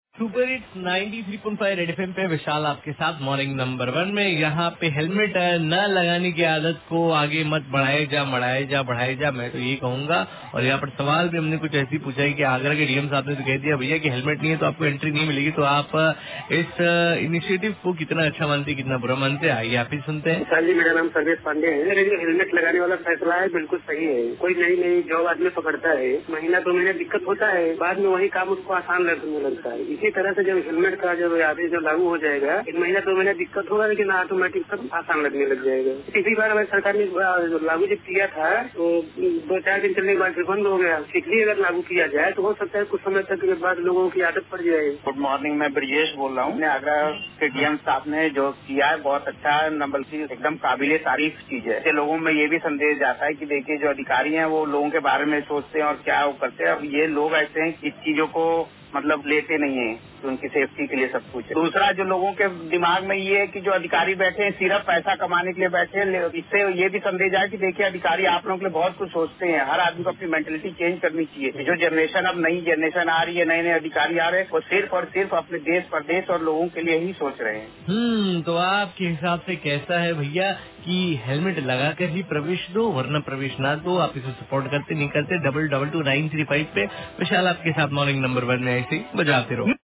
WITH CALLER